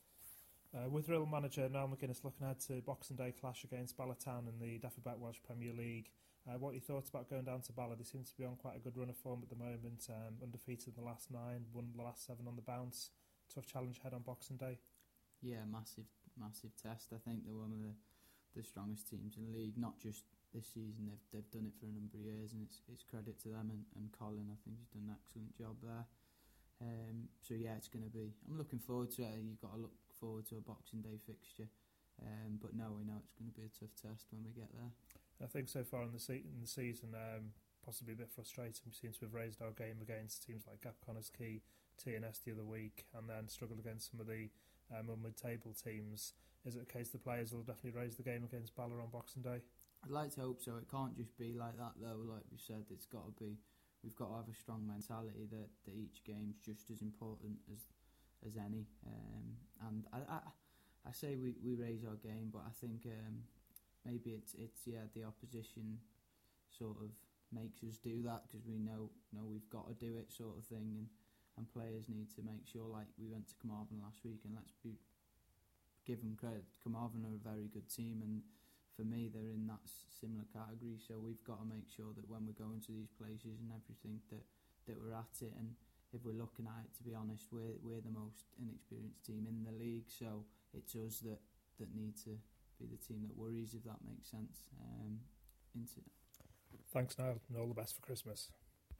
Pre match Interview Bala Town Away